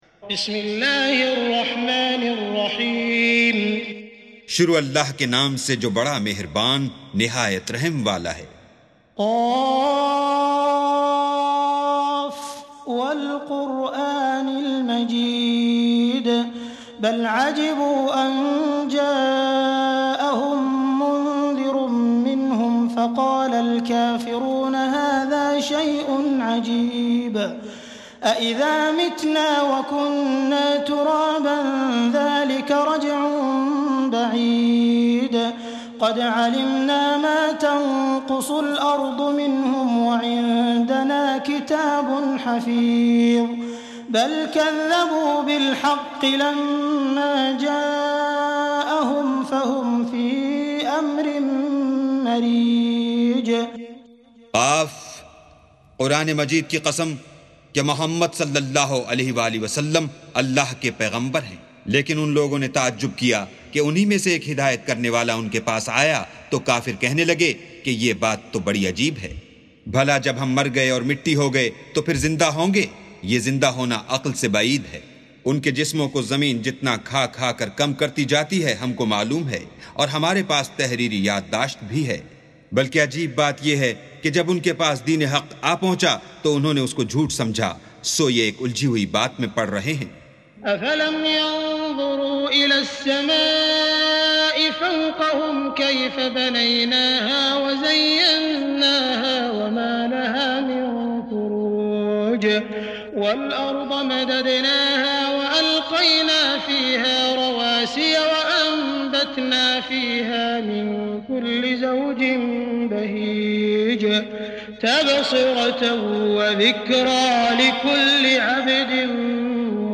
سُورَةُ ق بصوت الشيخ السديس والشريم مترجم إلى الاردو